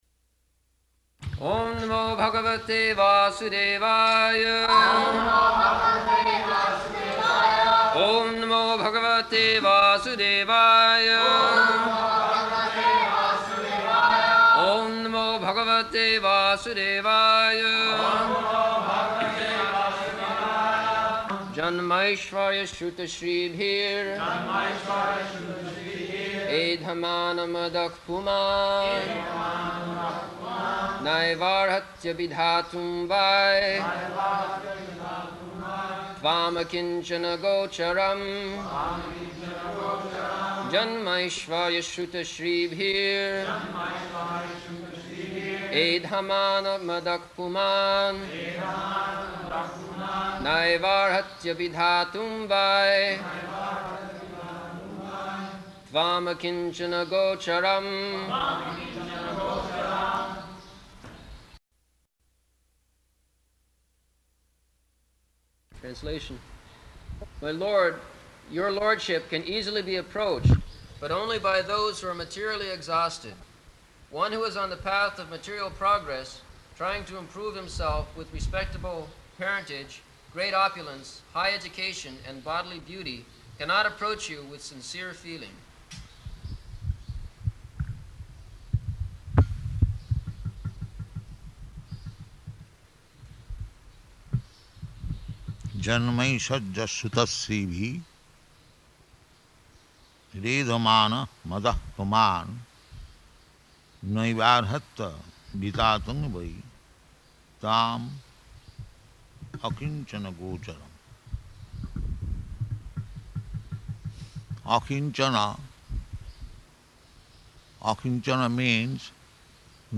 -- Type: Srimad-Bhagavatam Dated: October 6th 1974 Location: Māyāpur Audio file
[devotees repeat] [leads chanting of verse, etc.]